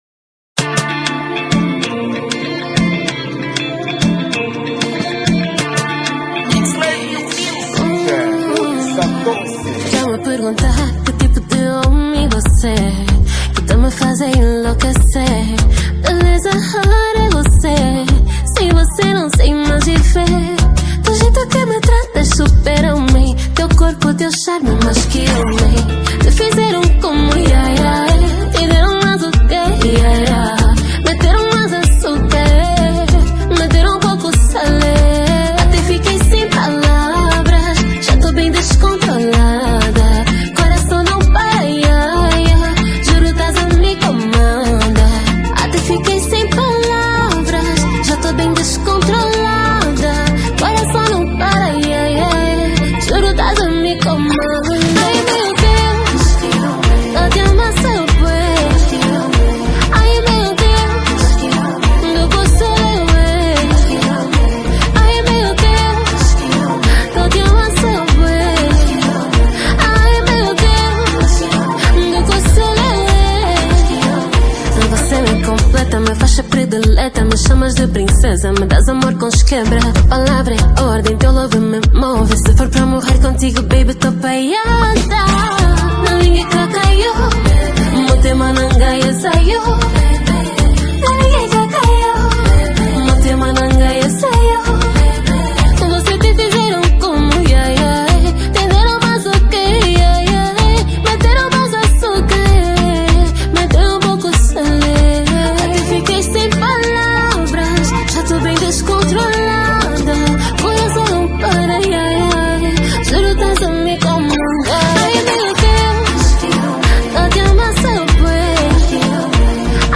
| Kizomba